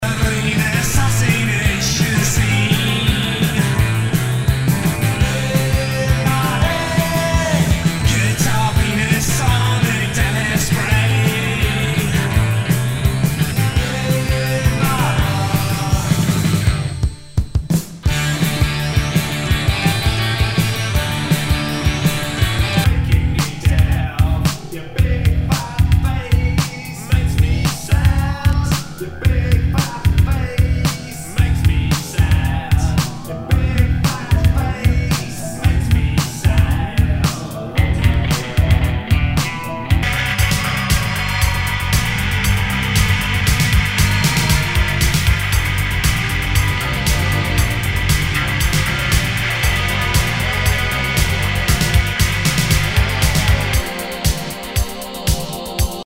ROCK/POPS/INDIE
ナイス！インディーロック！